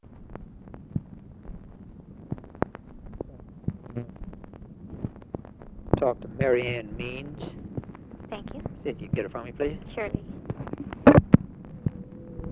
Conversation with JACK VALENTI and TELEPHONE OPERATOR, January 29, 1964